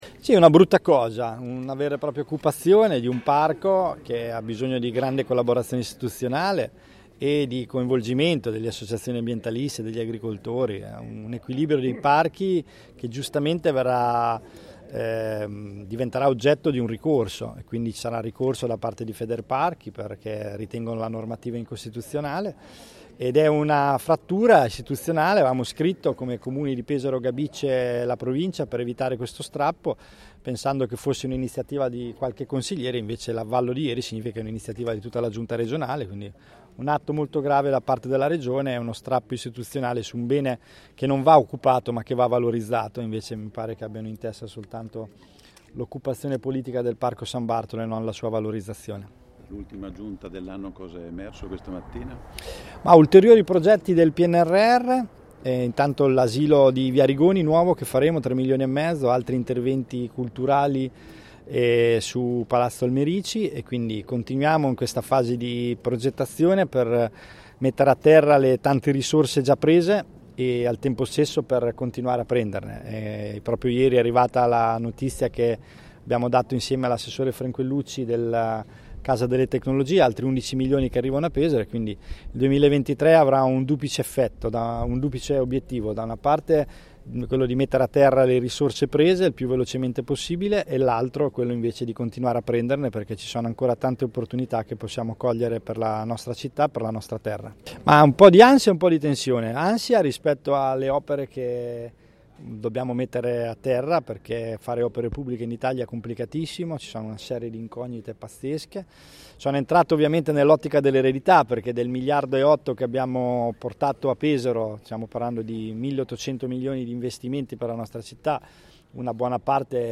Ultima conferenza del 2022 per il Sindaco Matteo Ricci, che questa mattina ha riunito la stampa pesarese alla Casetta Vaccaj per fare il bilancio dell’anno che sta per concludersi, guardando con «ottimismo» al 2023. Tutti gli argomenti affrontati dal Sindaco in conferenza, illustrati ai nostri microfoni nella sua intervista.